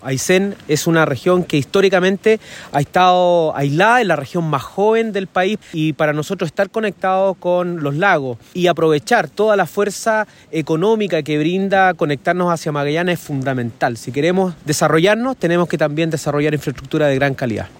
A los pies del Volcán Chaitén, en medio de la característica llovizna del sur, los gobernadores de la zona sur austral del país, firmaron su compromiso para trabajar en pos de la conectividad denominada “Chile por Chile”.